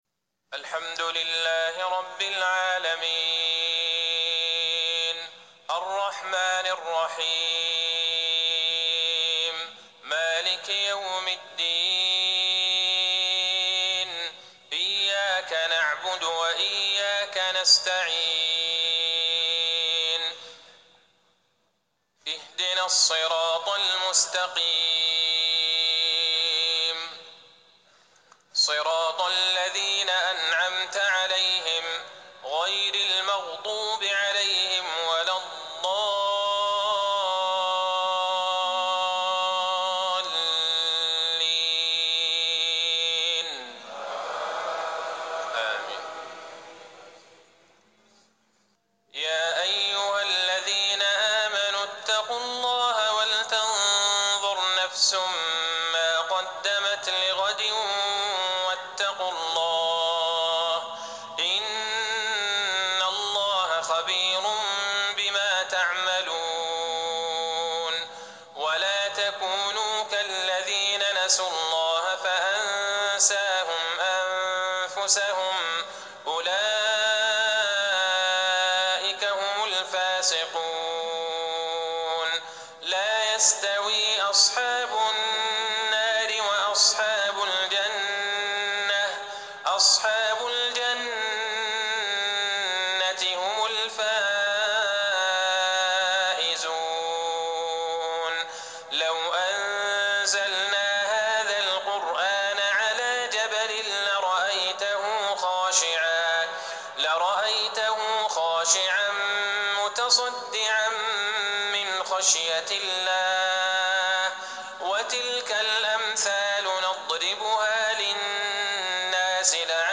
صلاة العشاء 1-5-1440هـ من سورتي الحشر والقيامة | Isha 7-1-2019 prayer from Surah al-Hashr and al-Qiyamah > 1440 🕌 > الفروض - تلاوات الحرمين